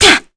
Kara-Vox_Attack2_kr.wav